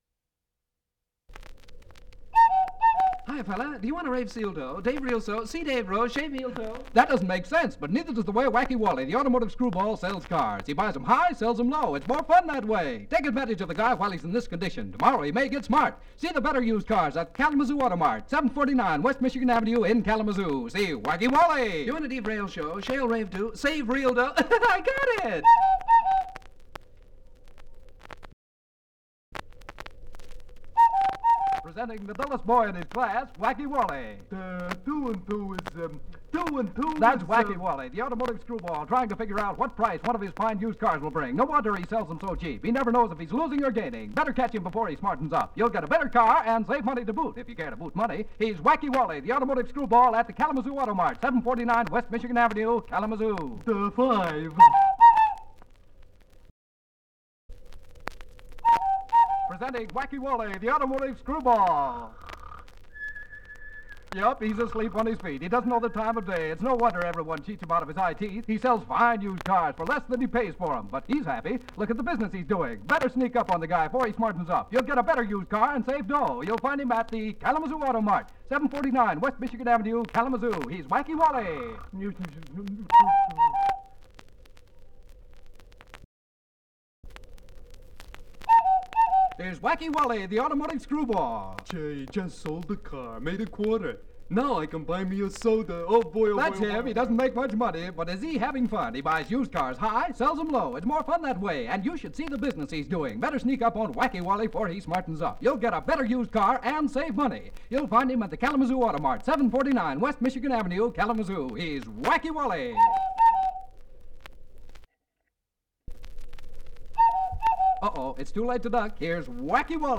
Several short radio commercials chain breaks for Kalamazoo Auto Mart